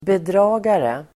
Uttal: [²bedr'a:gare]